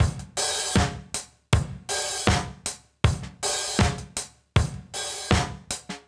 Index of /musicradar/sampled-funk-soul-samples/79bpm/Beats
SSF_DrumsProc2_79-03.wav